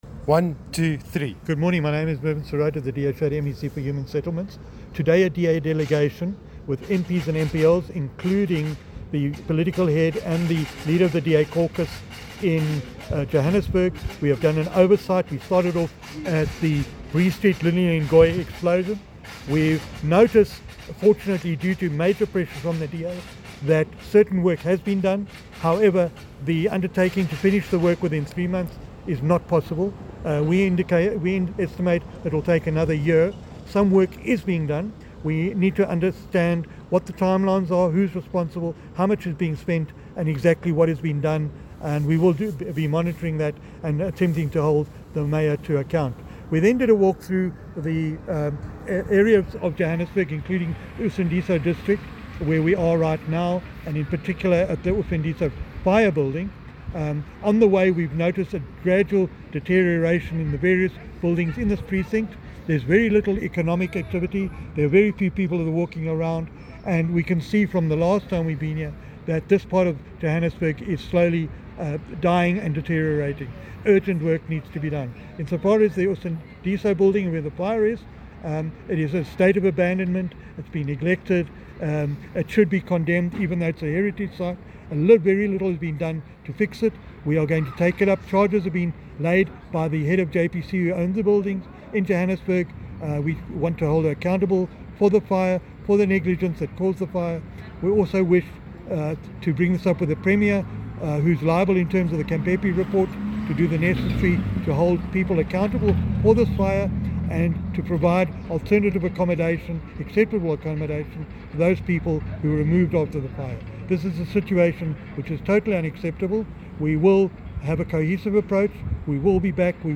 soundbite by Mervyn Cirota MPL